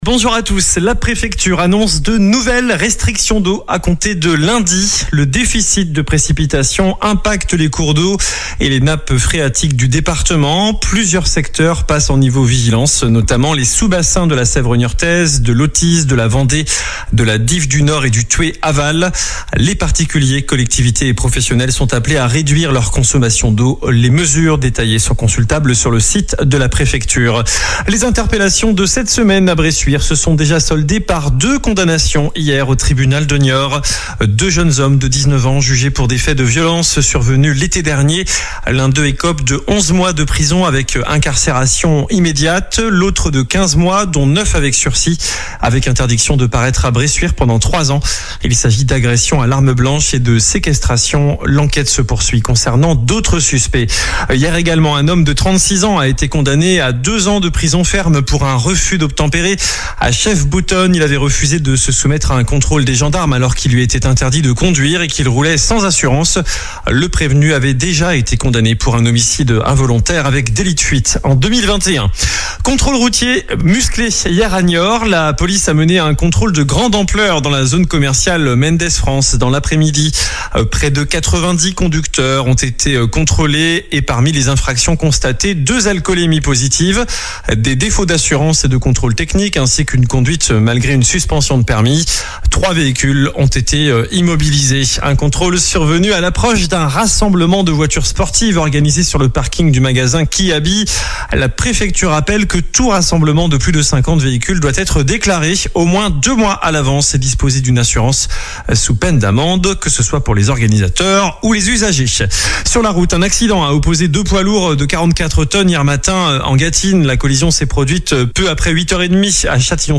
infos Deux-Sèvres 10h20